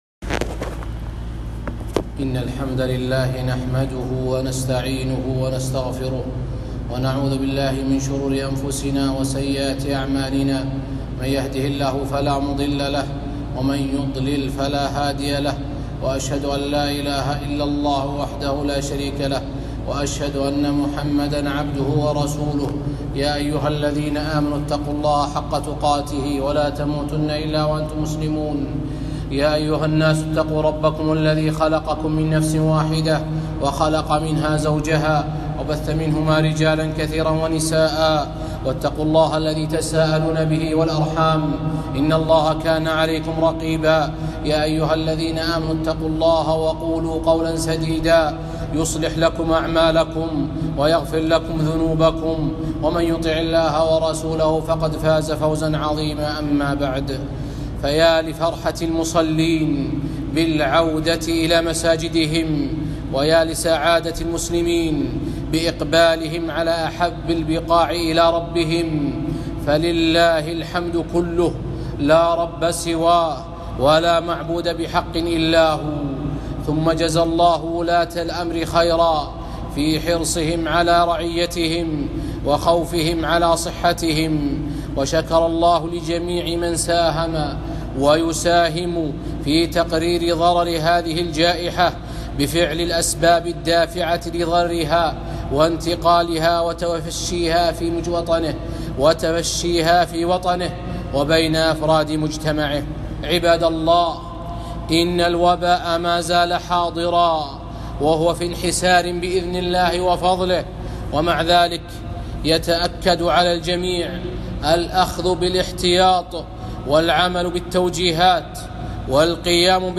خطبة - العودة إلى المساجد